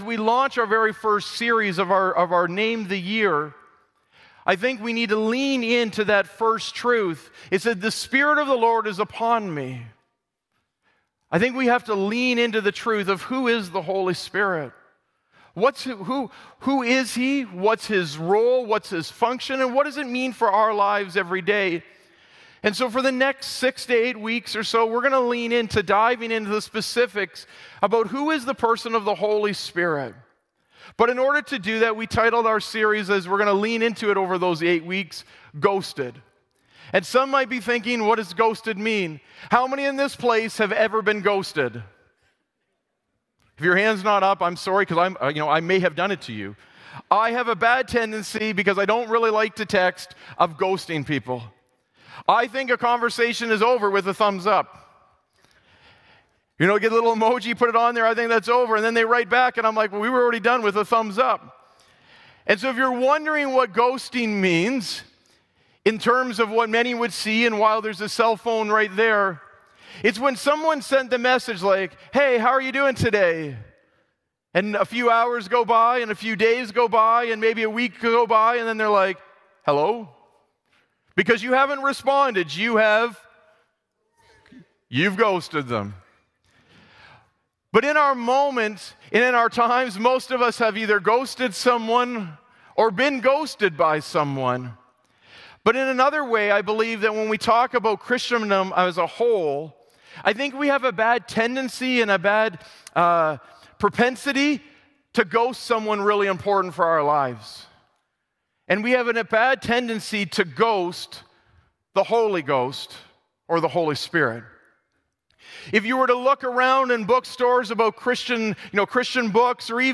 Sermon Podcast